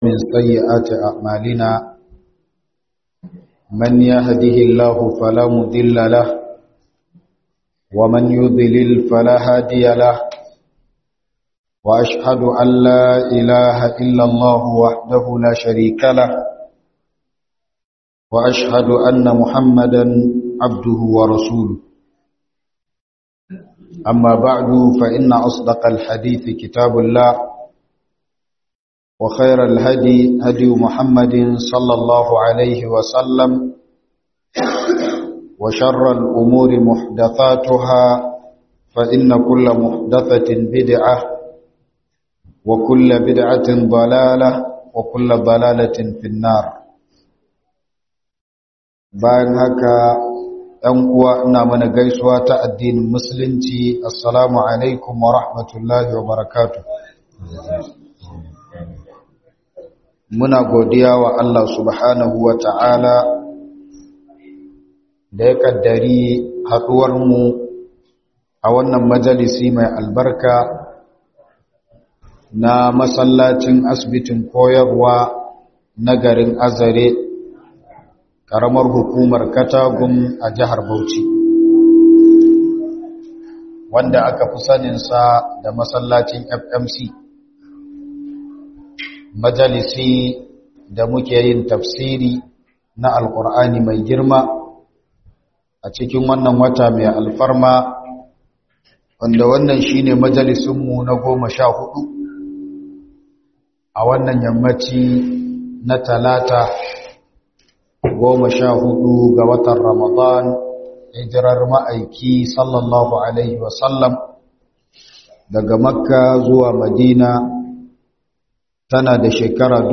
Ramadan Tafsir